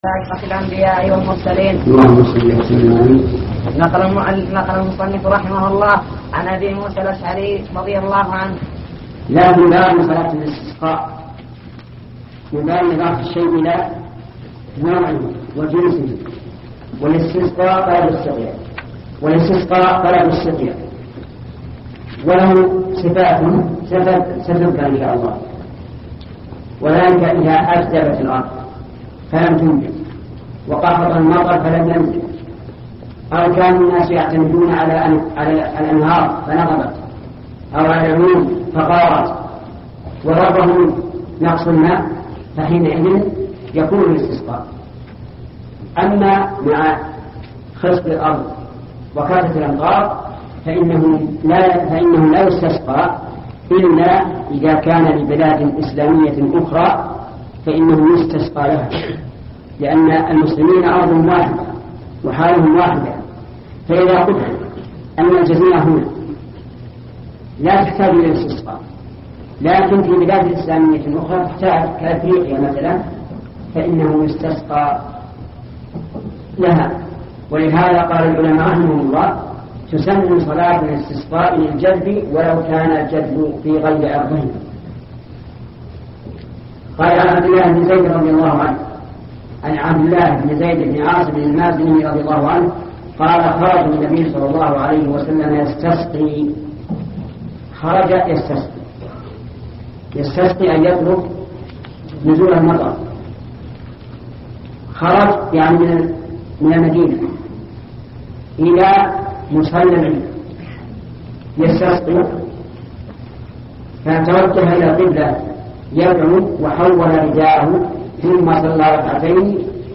الدرس الرابع والثمانون